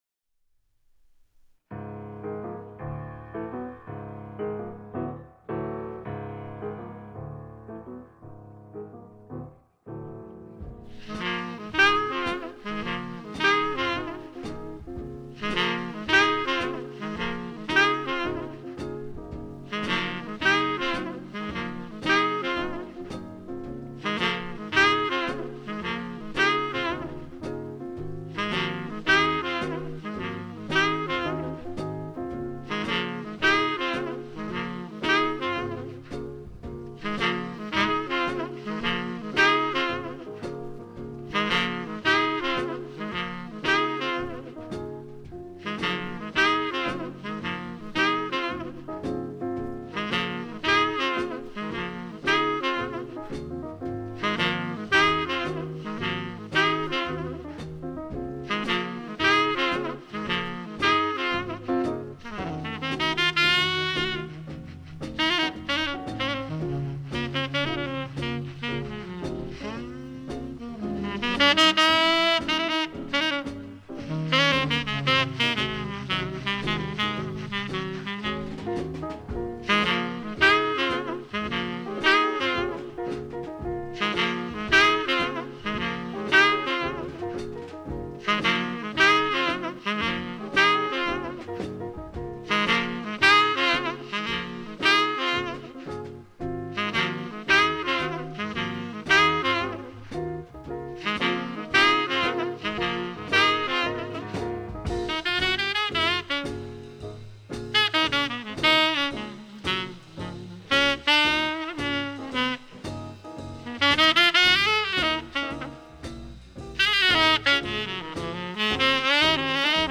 swinging, soulful document of jazz as a living language.